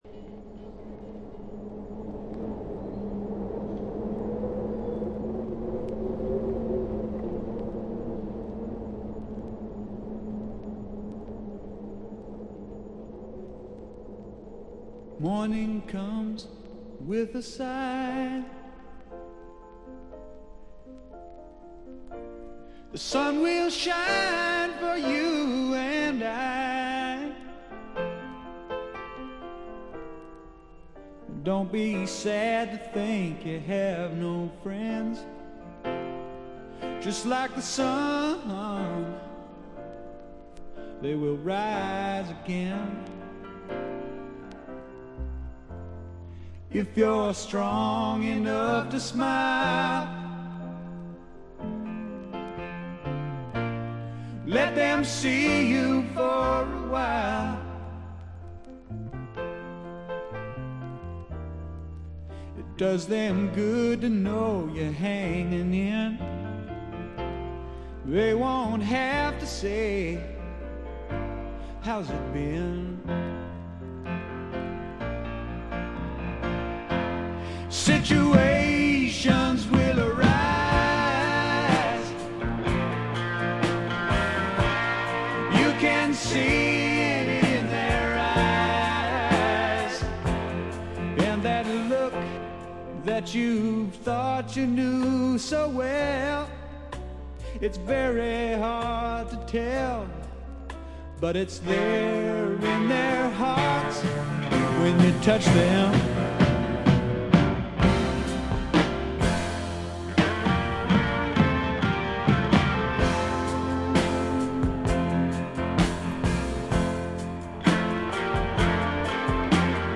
静音部でチリプチが聴かれますがおおむね良好に鑑賞できると思います。
試聴曲は現品からの取り込み音源です。